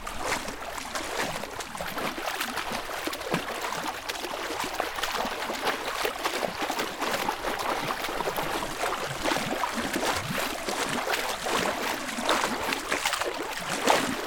물장구.mp3